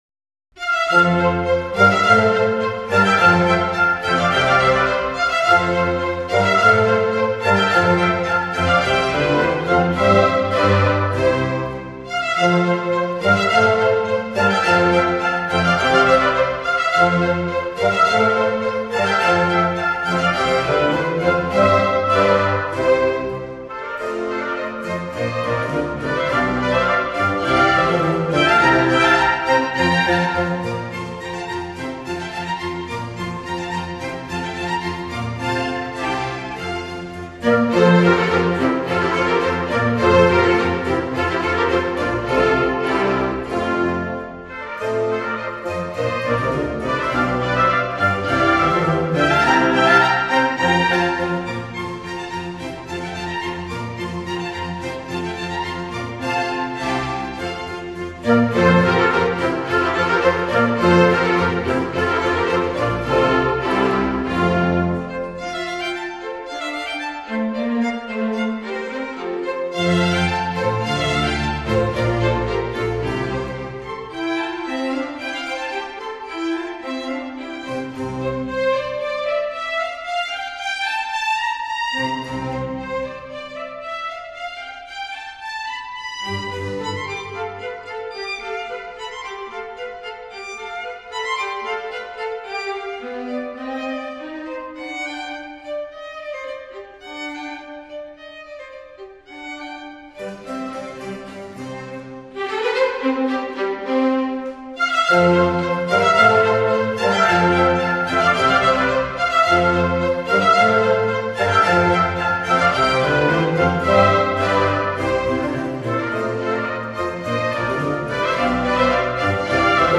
嘉禾舞曲 00:05:12